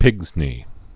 (pĭgznē)